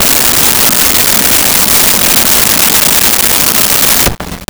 Lofi Drum Roll 02
LoFi Drum Roll 02.wav